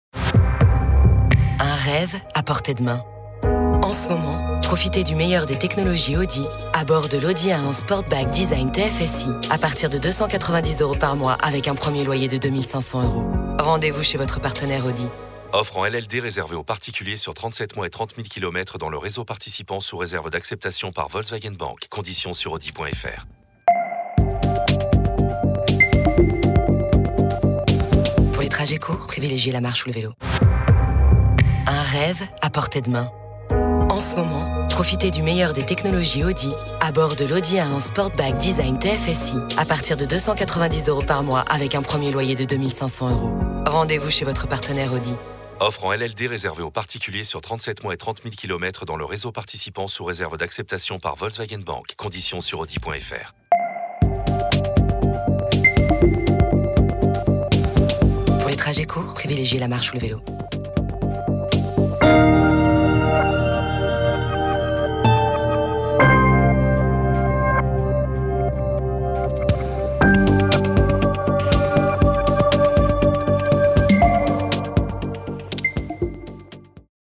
Accueil téléphonique
L’enregistrement de vos messages est réalisé par des professionnels dans notre studio d’enregistrement.